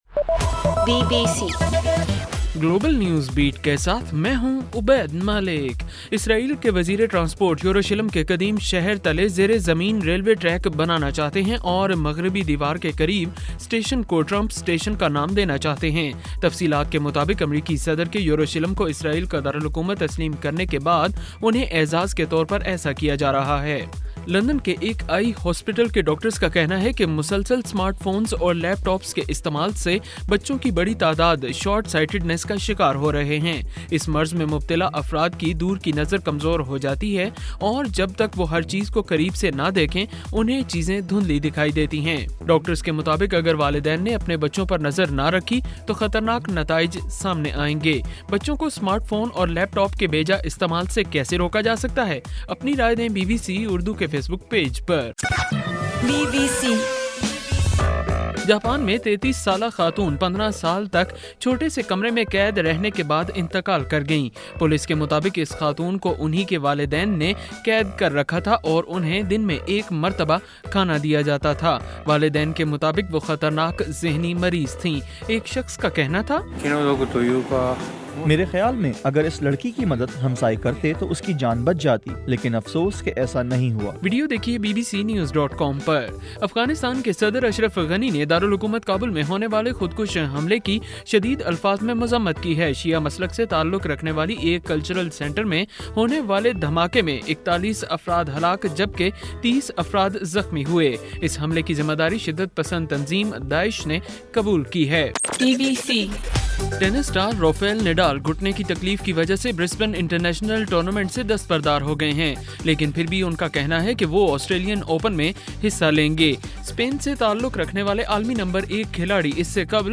گلوبل نیوز بیٹ بُلیٹن اُردو زبان میں رات 8 بجے سے صبح 1 بجے ہرگھنٹےکے بعد اپنا اور آواز ایفایم ریڈیو سٹیشن کے علاوہ ٹوئٹر، فیس بُک اور آڈیو بوم پر